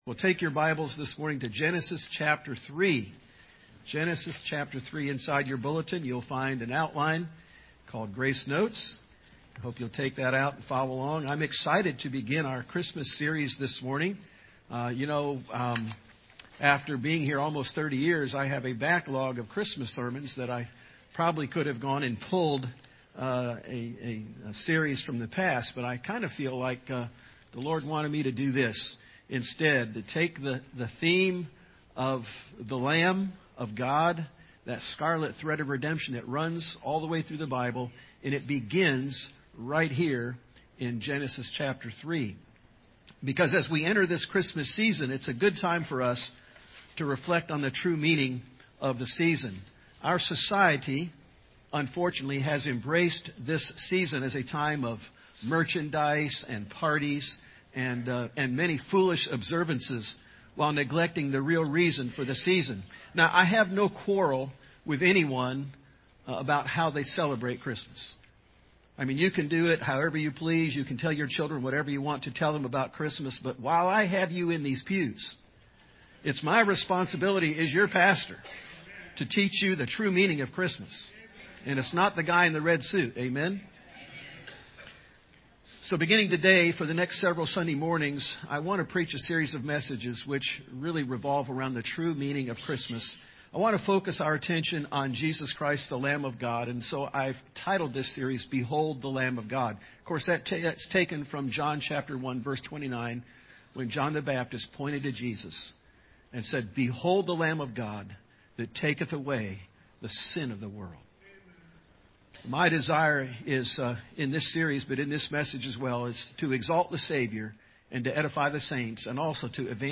2020 The Promise of the Lamb Preacher